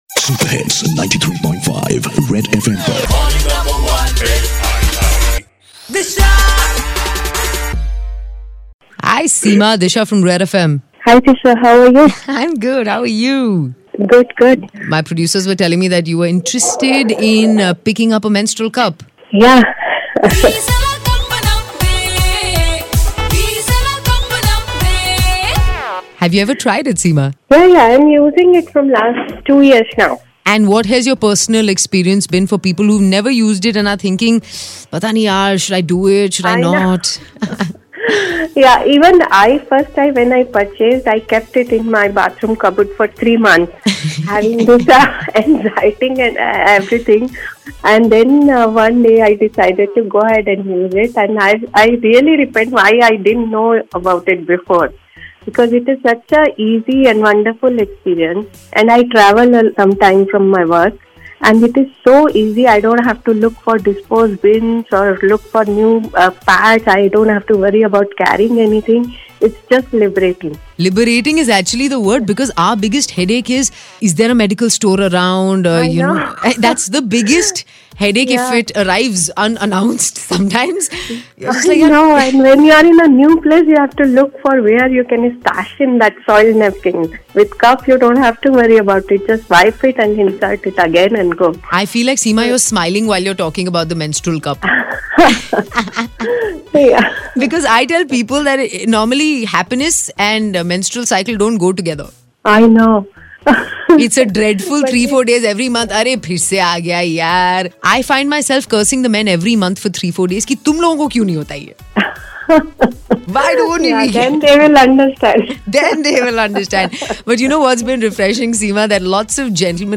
a listener shares how menstrual cups are good for Environment